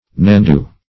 nandou - definition of nandou - synonyms, pronunciation, spelling from Free Dictionary
Nandou \Nan"dou\, Nandu \Nan"du\, n. [Braz. nhandu or yandu.]